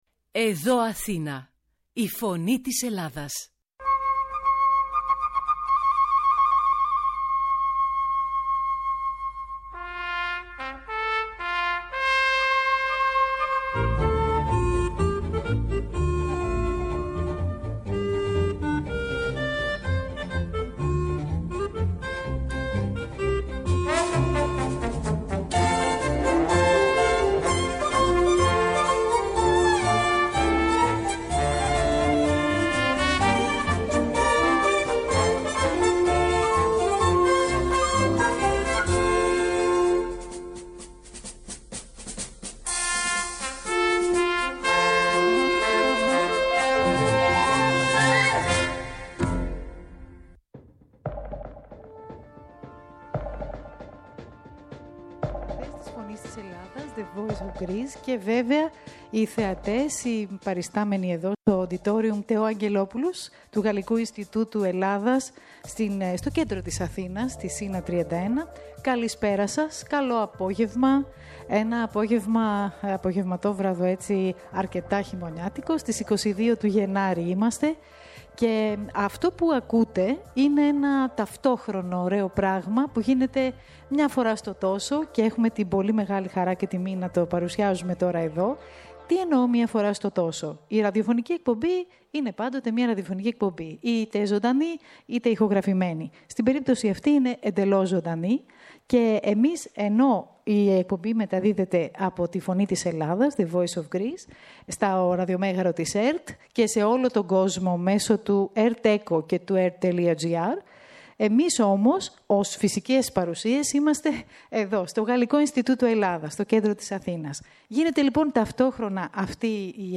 Ειδική ζωντανή εκπομπή από το Γαλλικό Ινστιτούτο Ελλάδος